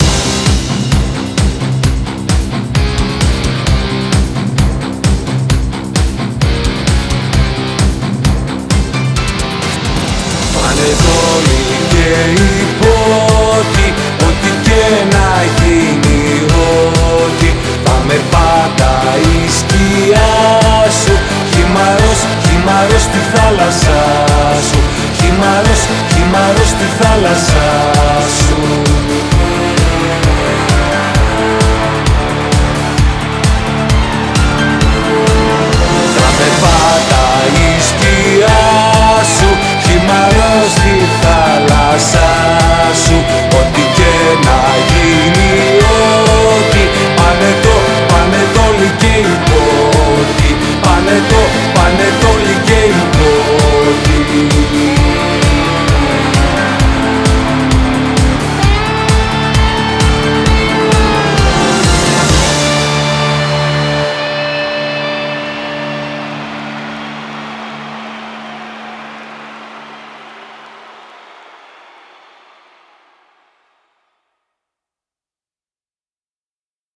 Ηλεκτρική Κιθάρα